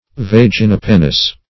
Search Result for " vaginopennous" : The Collaborative International Dictionary of English v.0.48: Vaginopennous \Vag`i*no*pen"nous\, a. [L. vagina a sheath + penna a feather, pl. pennae a wing.]
vaginopennous.mp3